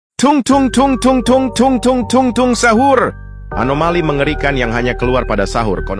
Tung Tung Sahur… sound effect
Thể loại: Âm thanh meme Việt Nam
tung-tung-sahur-sound-effect-www_tiengdong_com.mp3